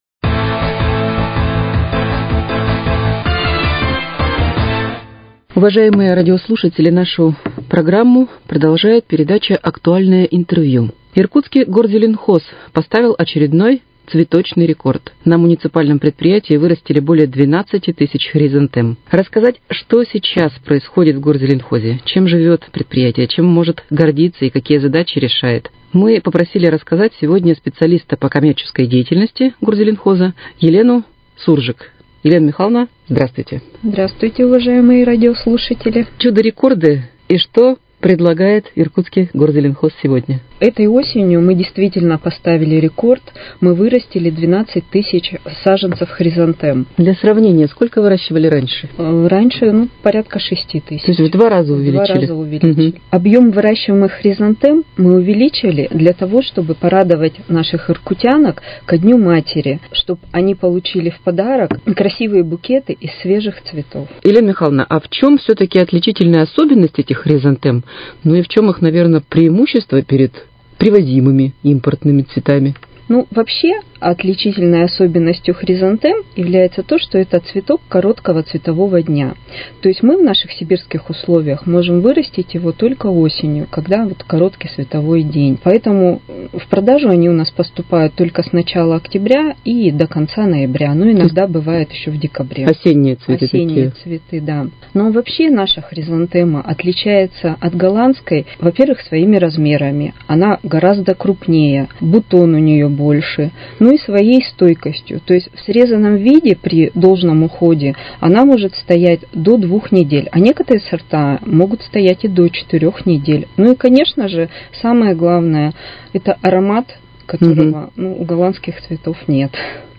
Актуальное интервью: Иркутский «Горзеленхоз» поставил цветочный рекорд